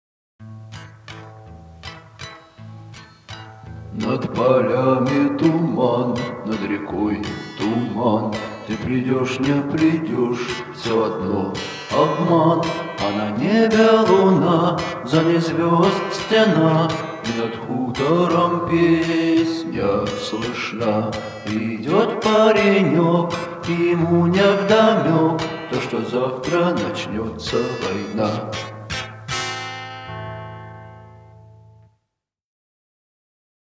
Музыка пронизана меланхолией